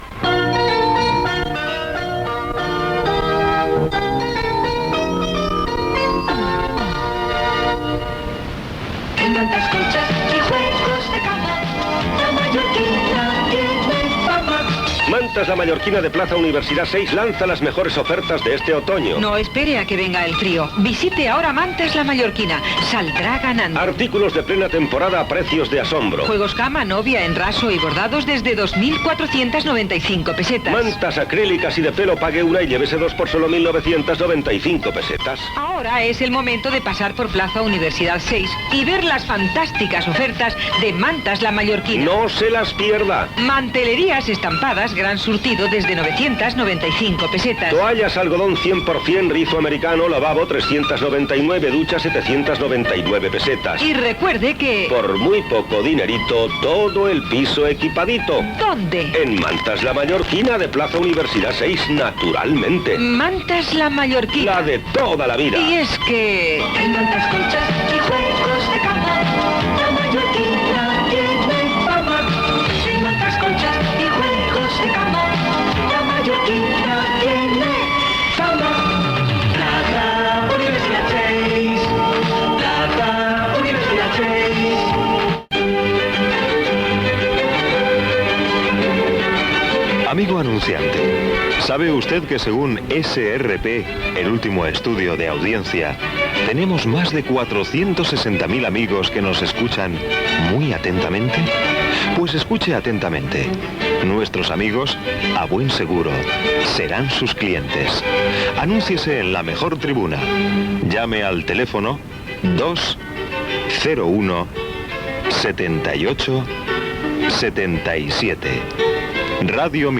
Sintonia de la cadena, publicitat, dades de l'audiència de l'emissora i telèfon per anunciar-se, propaganda electoral del Partido Popular